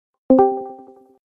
Efeito sonoro Discord join
Categoria: Efeitos sonoros
efeito-sonoro-discord-join-pt-www_tiengdong_com.mp3